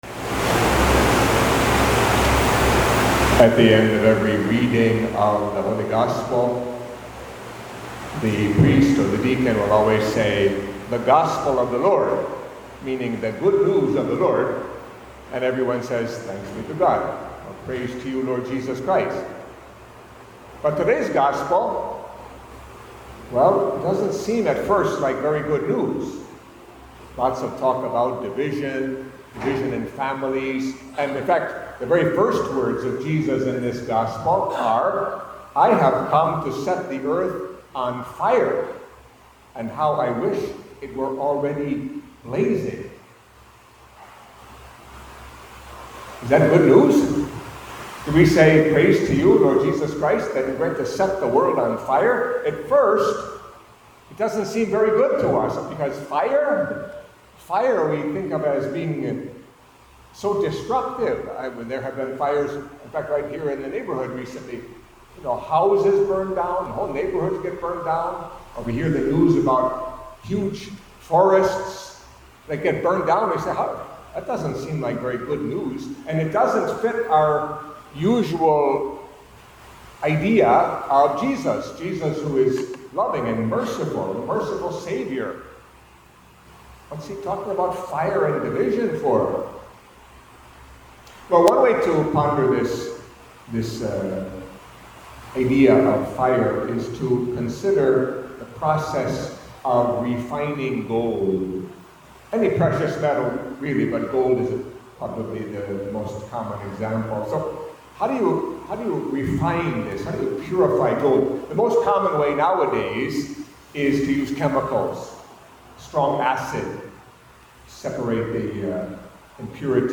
Catholic Mass homily for the Twentieth Sunday in Ordinary Time